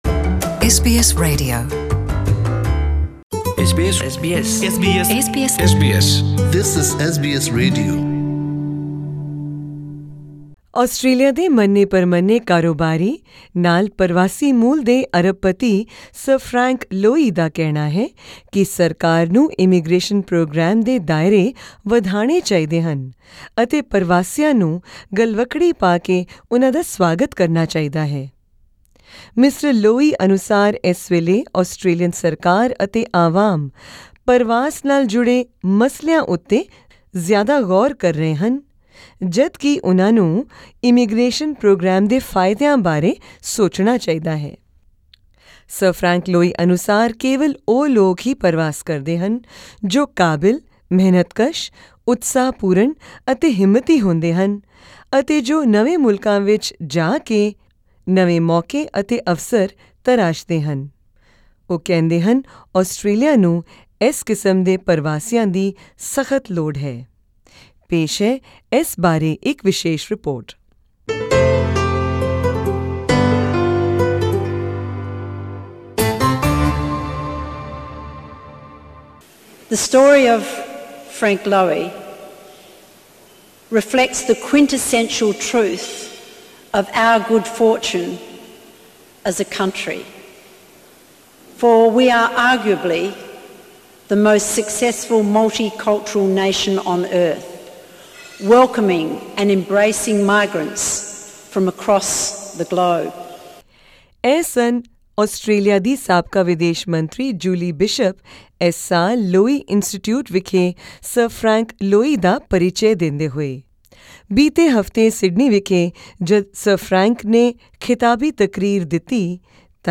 Lowy Institute Chairman, Sir Frank Lowy AC delivering Lowy Institute lecture held at Town Hall, in Sydney, Thursday, September 13, 2018.